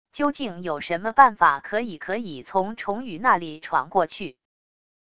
究竟有什么办法可以可以从虫雨那里闯过去wav音频生成系统WAV Audio Player